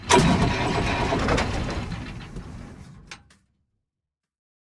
tank-no-fuel-1.ogg